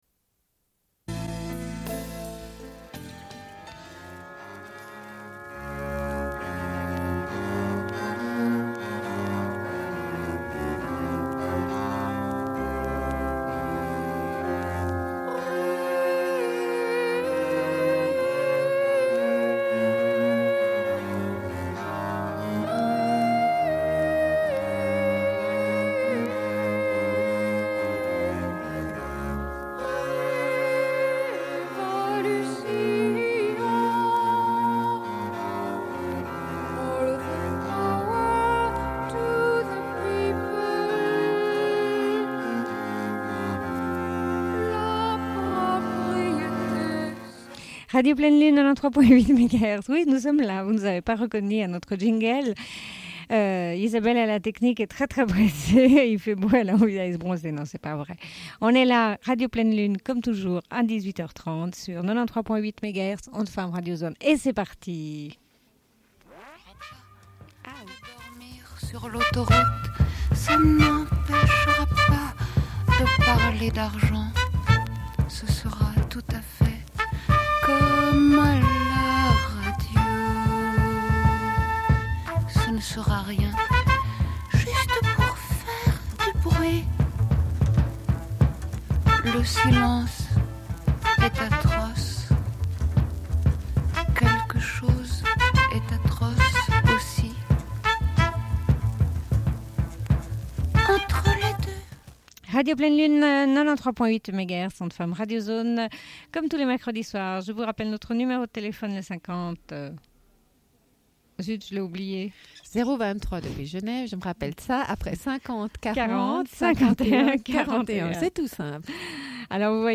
Bulletin d'information de Radio Pleine Lune du 13.05.1992 - Archives contestataires
Une cassette audio, face B00:28:46